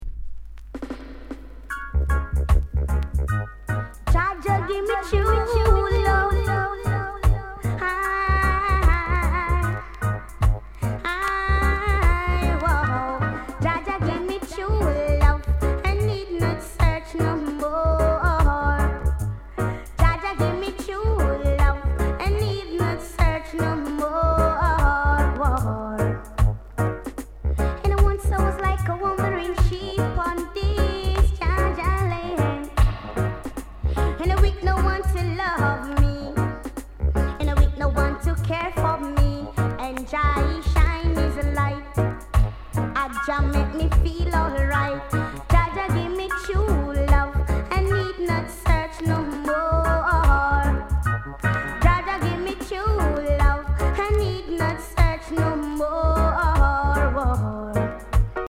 Sound Condition VG�ܡ�EX-